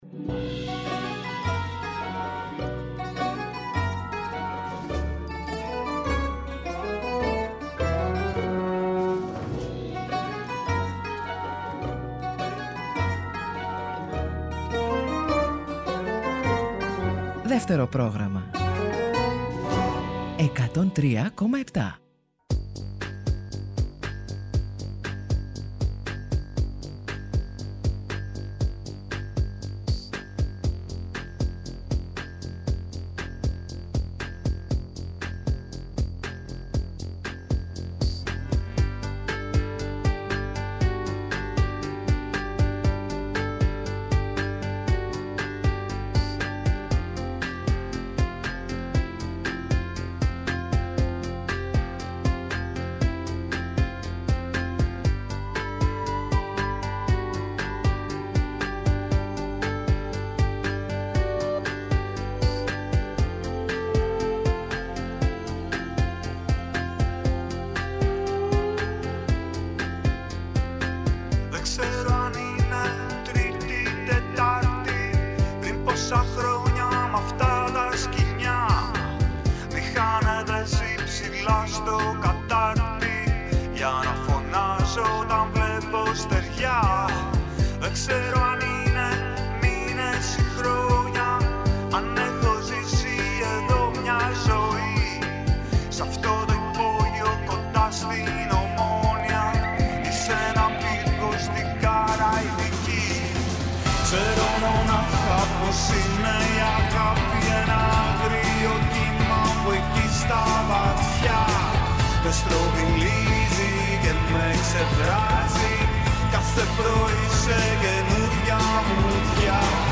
Δεύτερο Πρόγραμμα προτείνει τραγούδια από το έντεχνο ελληνικό ρεπερτόριο